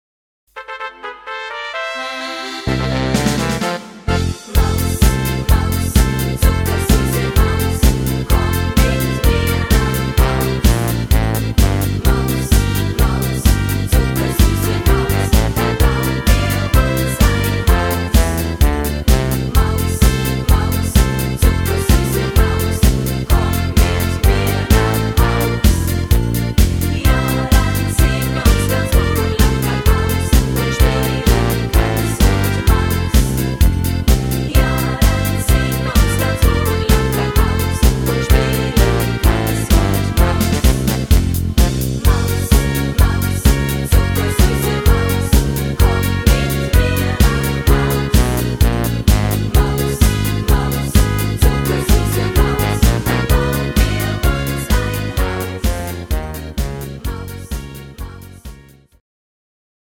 Rhythmus  Party Polka
Art  Deutsch, Fasching und Stimmung, Medleys